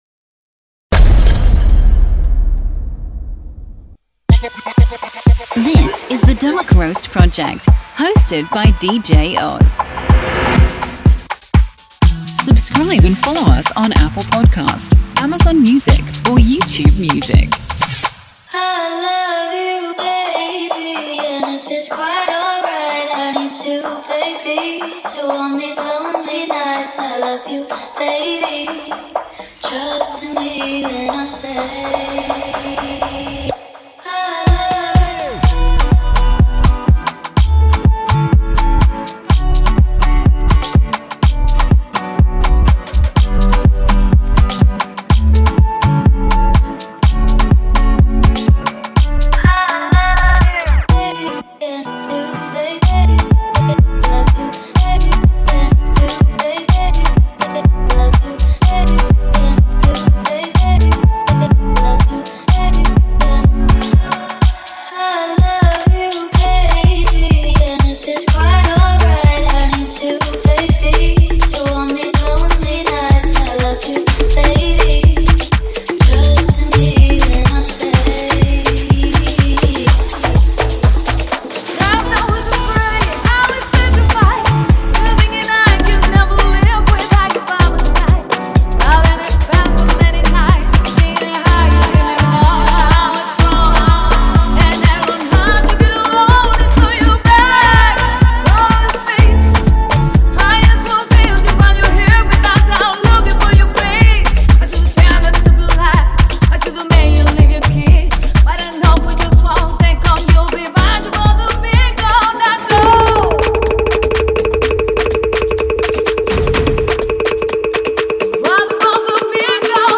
PARTY MIX...